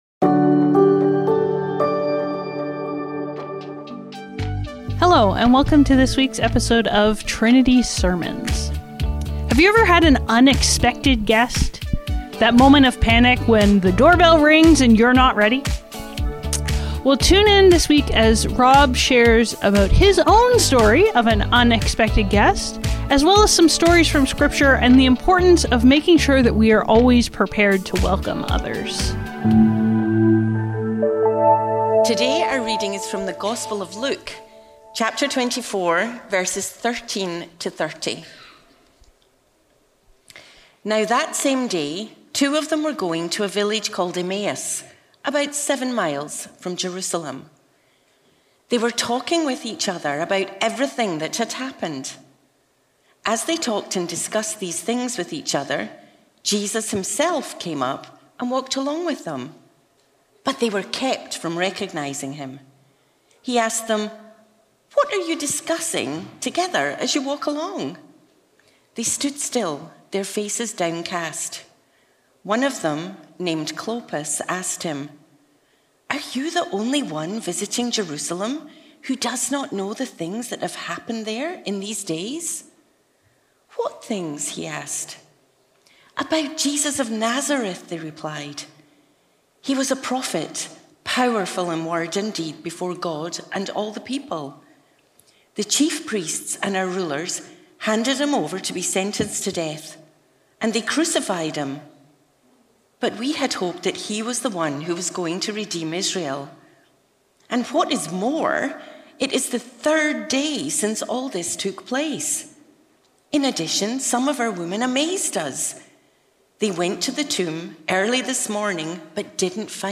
Trinity Streetsville - Guess Who’s Coming to Dinner? | Around The Table | Trinity Sermons